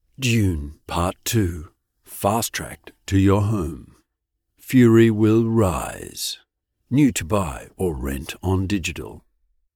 Male
English (Australian)
Adult (30-50), Older Sound (50+)
Silken baritone voice, for Documentary, Training videos and podcasts.
Movie Trailers